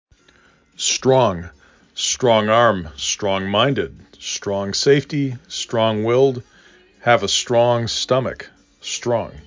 6 Letters, 1 Syllable
s t r aw N
s t r o N